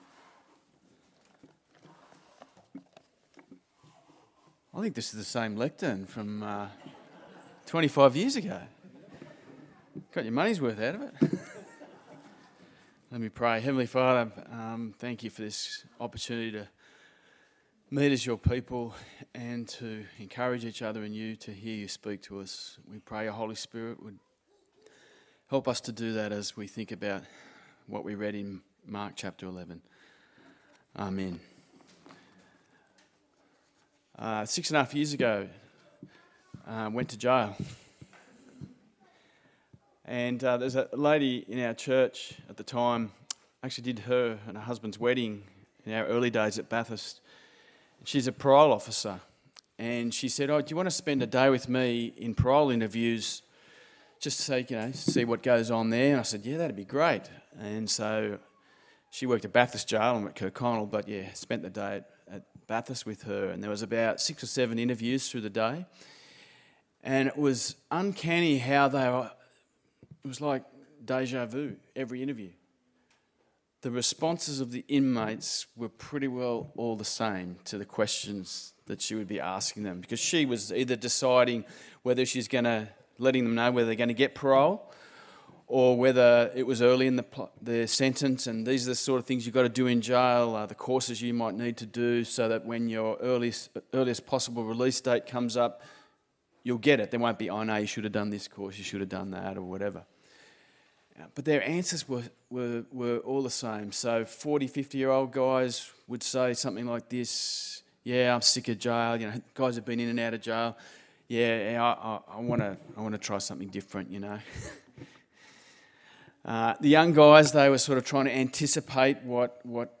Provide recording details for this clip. Mark Passage: Mark 11:1-33 Service Type: Sunday Service